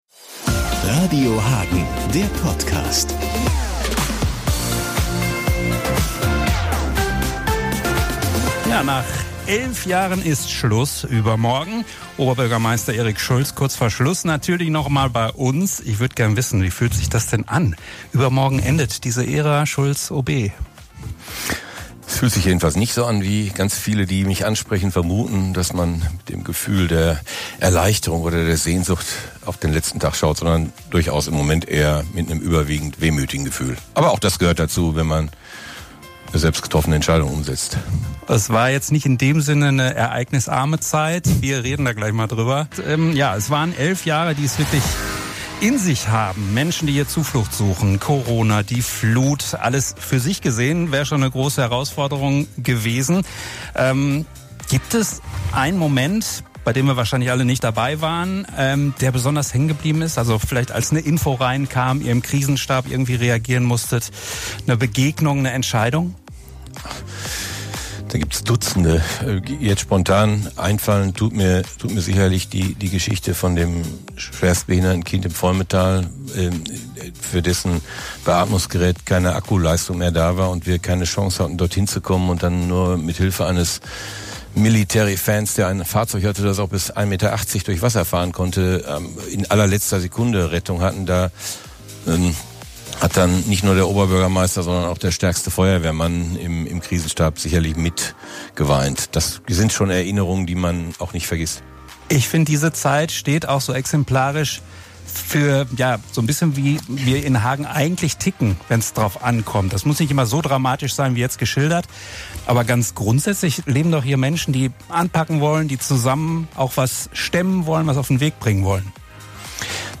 Nach elf Jahren endet für Erik Schulz die Zeit als Oberbürgermeister von Hagen. Bei seinem letzten Besuch in unserem Studio